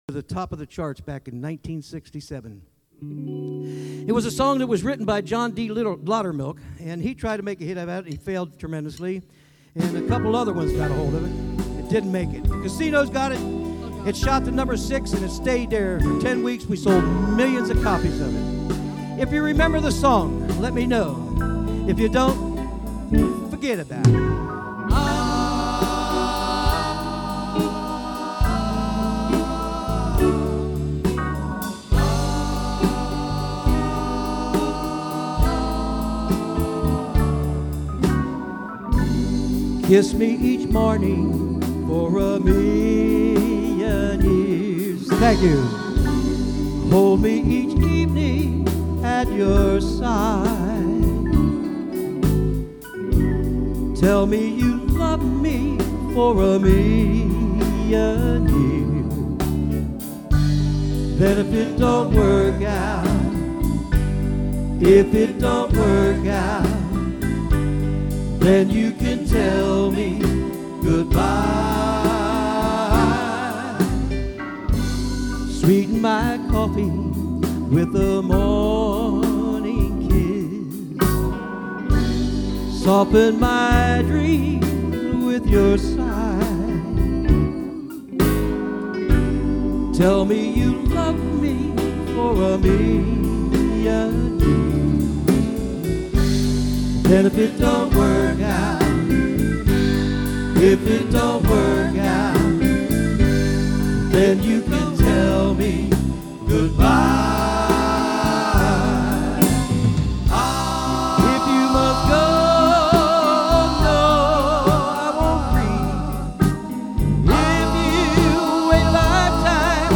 live
solos, nostalgia, doo wop